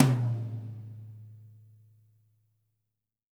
-TOM 2E   -L.wav